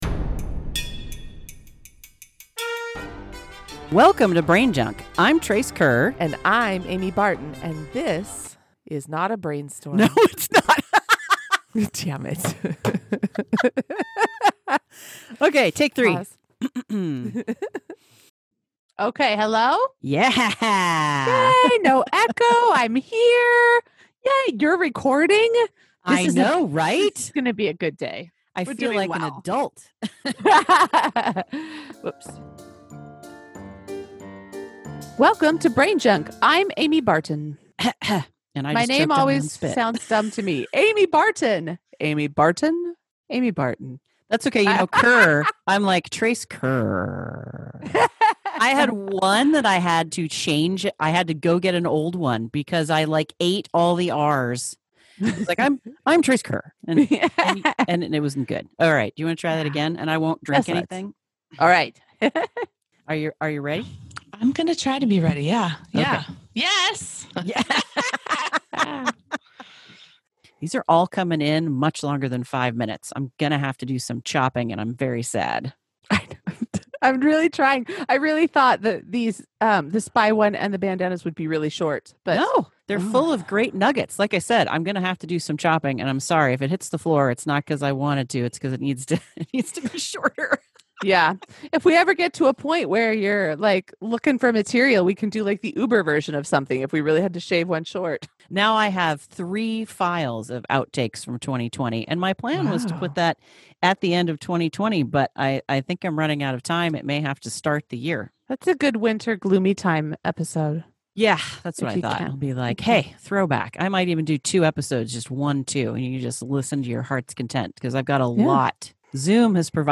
We use a few swear words, talk about illuminated manuscript penis trees, and yes, we may have talked about undescended testicles.
We went from working in person to recording remotely.